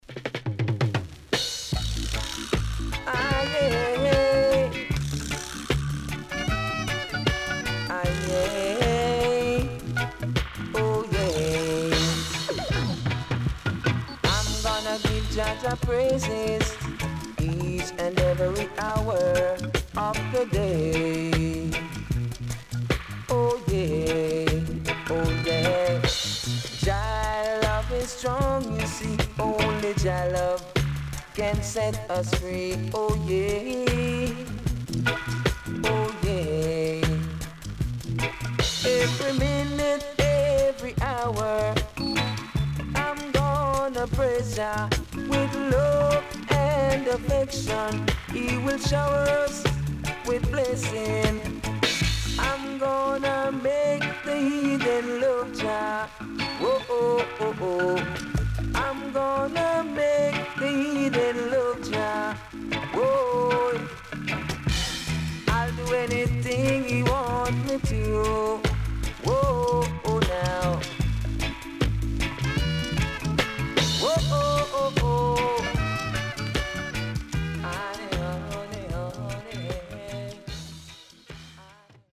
HOME > LP [DANCEHALL]  >  EARLY 80’s
SIDE A:プレス起因により所々チリノイズ入ります。